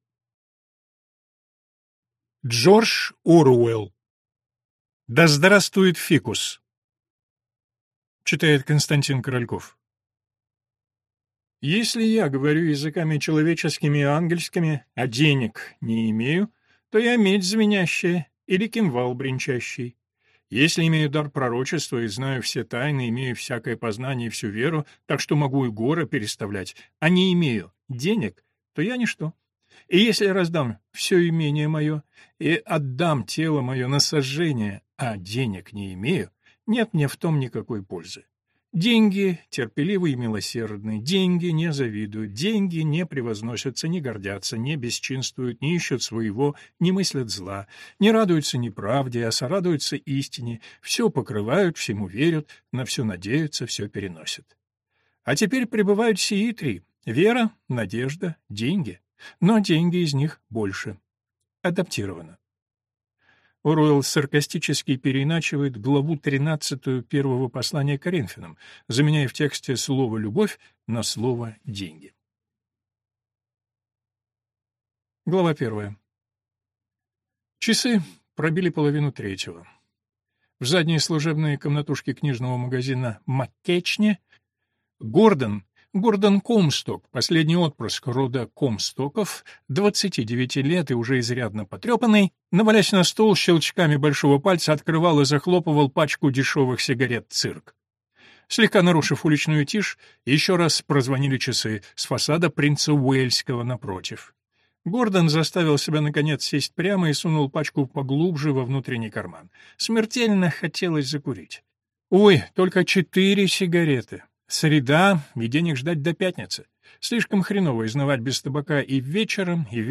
Аудиокнига Да здравствует фикус!